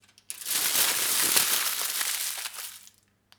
Foil screwed up 2
foil screwed up 2.wav